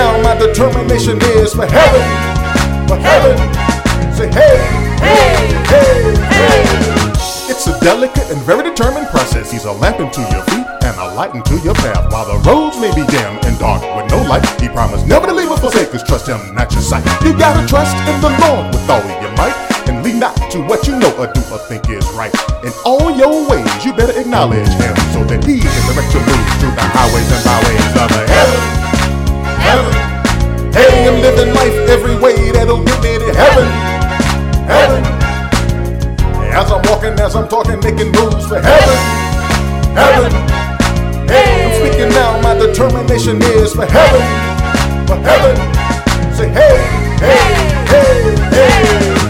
praise and worship